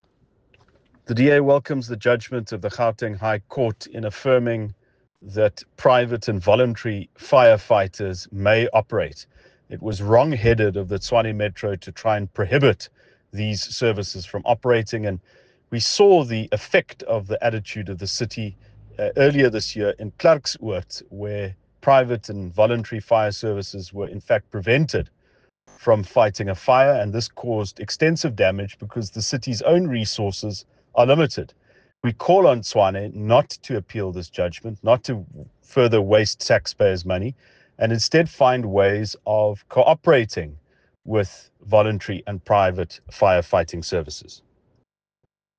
English and Afrikaans soundbites by Ald Cilliers Brink